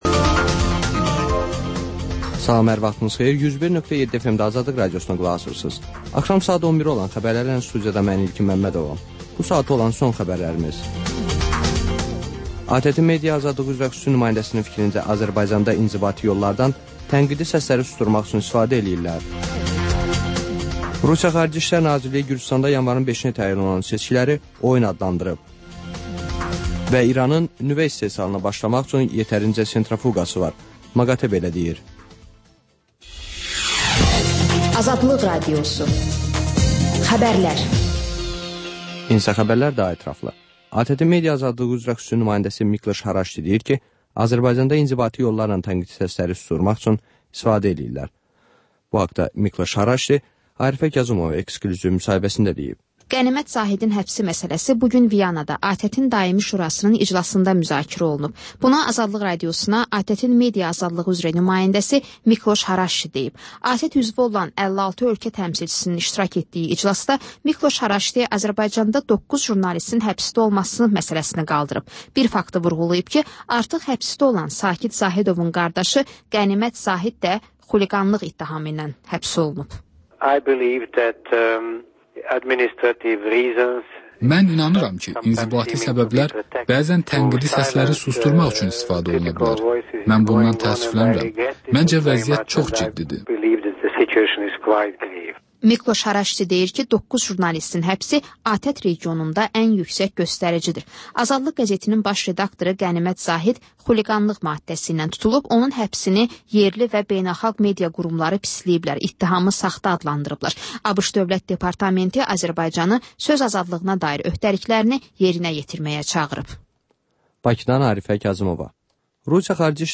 Xəbərlər, müsahibələr, hadisələrin müzakirəsi, təhlillər, sonda QAFQAZ QOVŞAĞI rubrikası: «Azadlıq» Radiosunun Azərbaycan, Ermənistan və Gürcüstan redaksiyalarının müştərək layihəsi